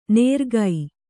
♪ nērgai